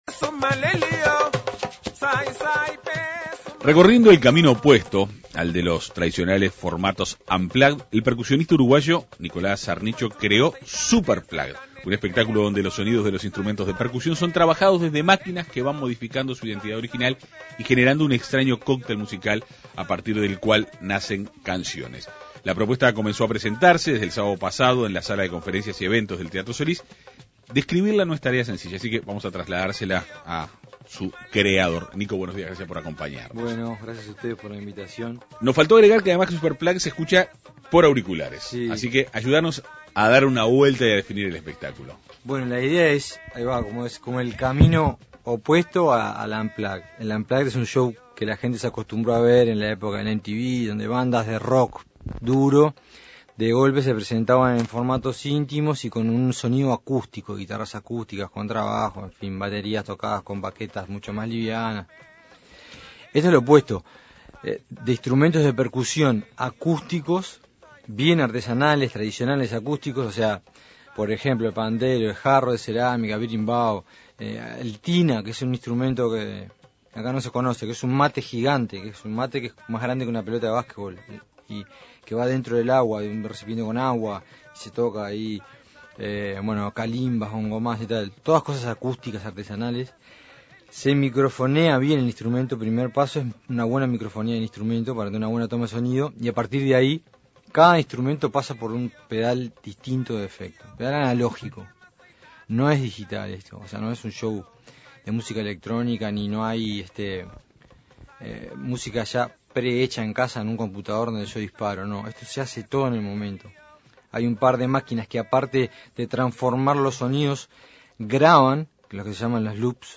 El músico dialogó en la Segunda Mañana de En Perspectiva.